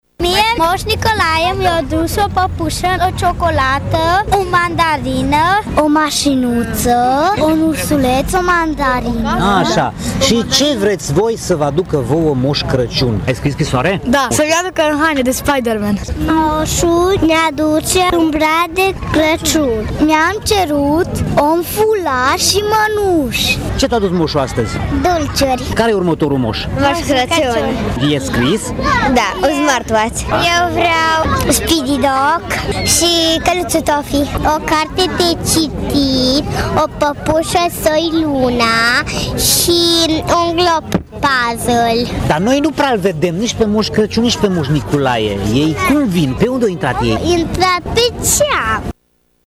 Peste 1000 de copii au participat la evenimentul din această seară, organizat de Primărie.
Personajul principal al zilei a fost Moș Nicolae, iar copiii au spus ce le-a dus moșul, precum și ce speră să le aducă fratele său, Moș Crăciun: